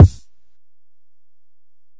Kick (13).wav